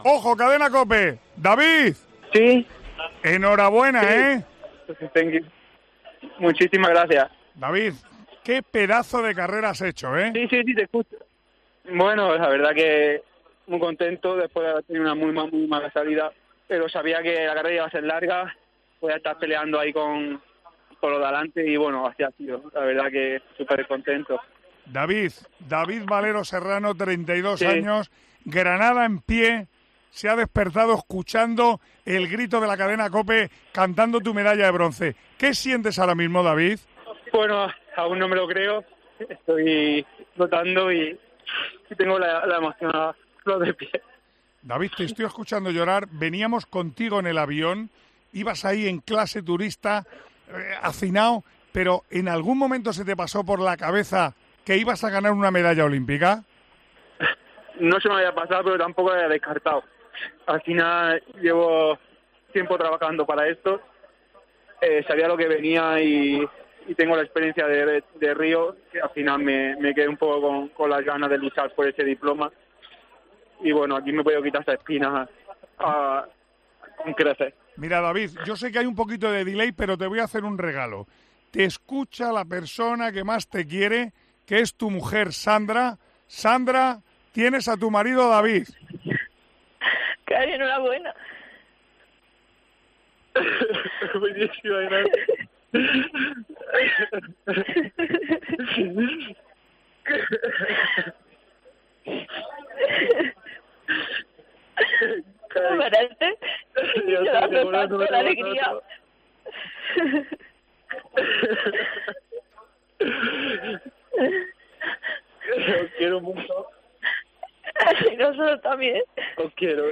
Tanto uno como el otro no pudieron aguantar las lágrimas y se emocionaron en antena.